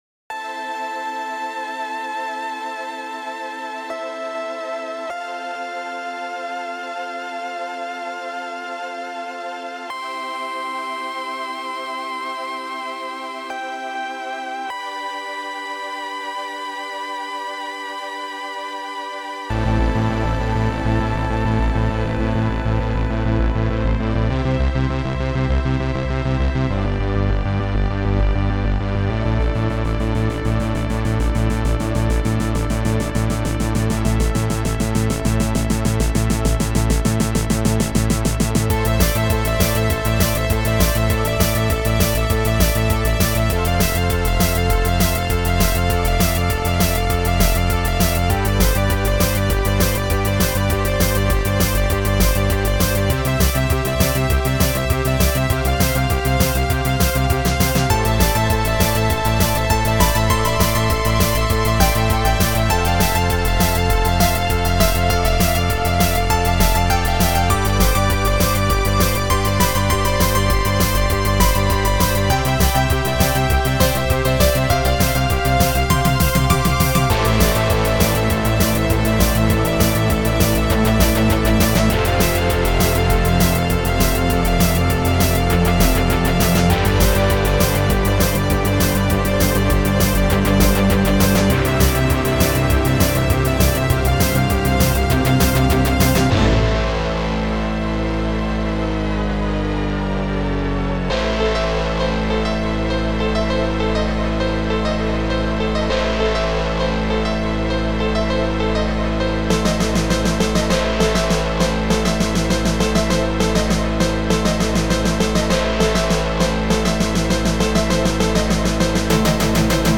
Kompo Musiikki
Alusta OpenMPT (Impulse Tracker, Modplug Tracker)